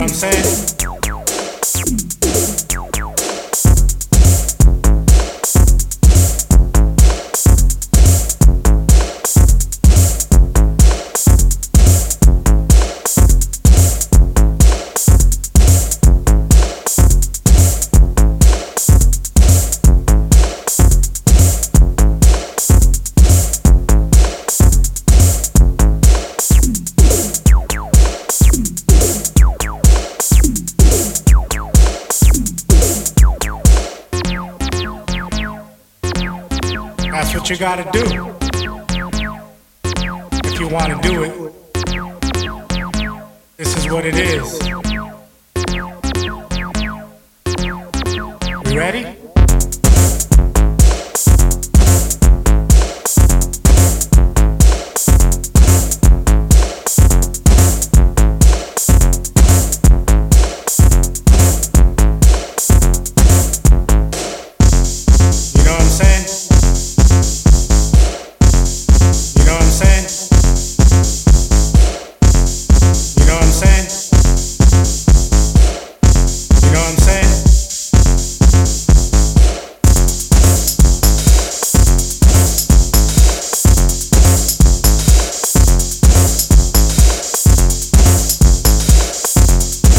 Effective electro-clashy acid tracks